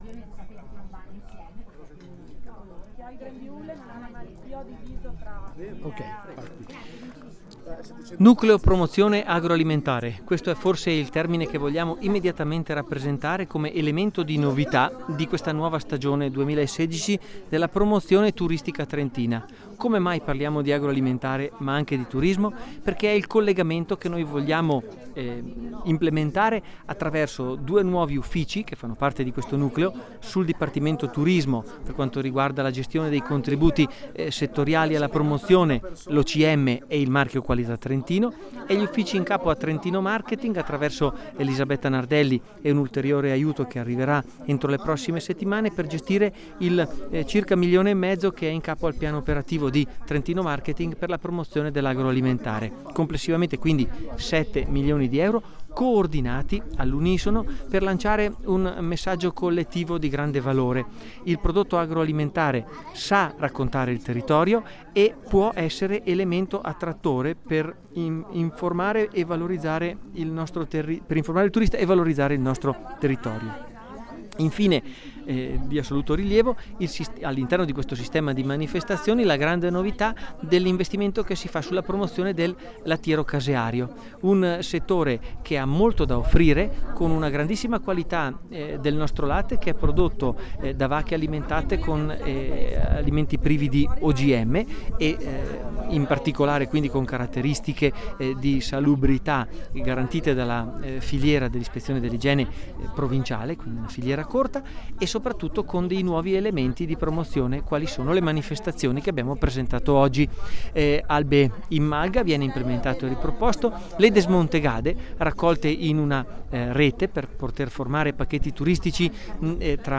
Intervista Assessore Dallapiccola.mp3